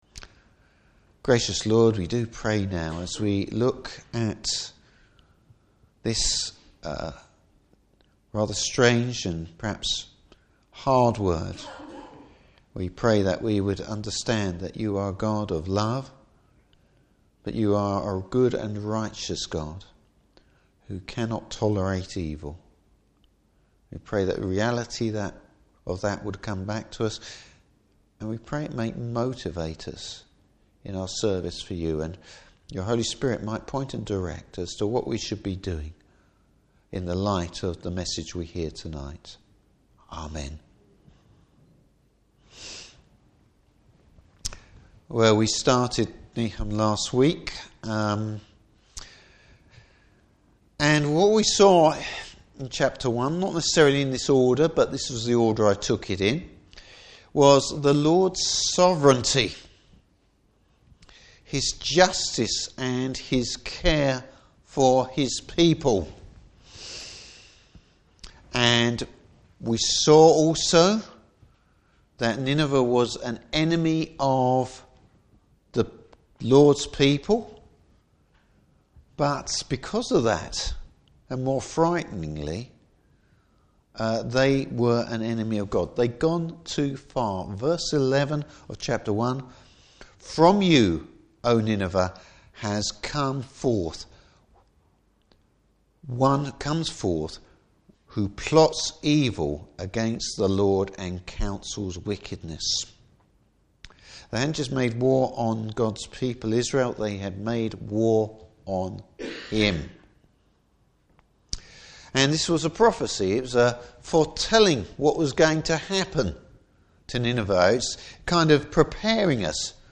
Service Type: Evening Service The fall of Nineveh!